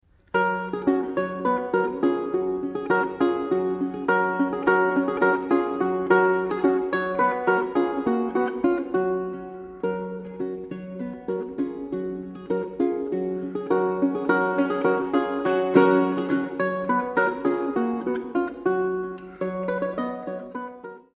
Renaissance and Baroque Guitar
Evropská kytarová hudba z 16. a 17. století
Kaple Pozdvižení svatého Kříže, Nižbor 2014